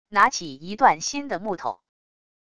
拿起一段新的木头wav音频